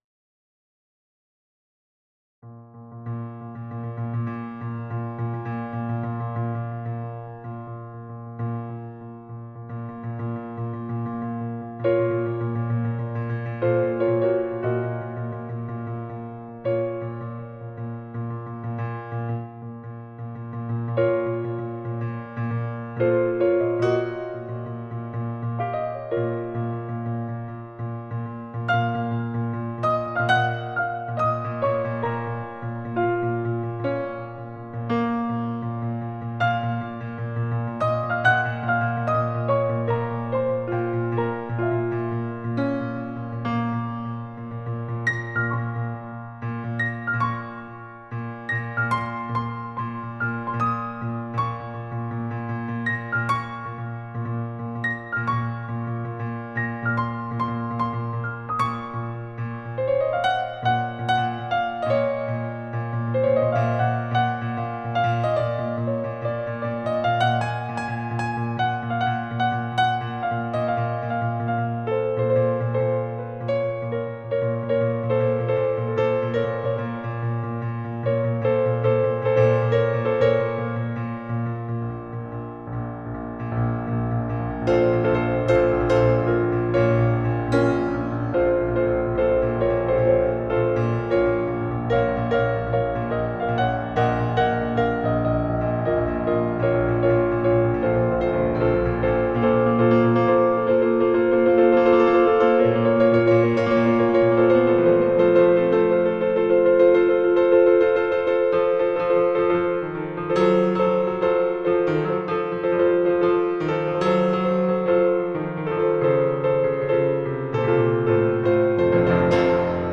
Prélude n°11 en Si♭ mineur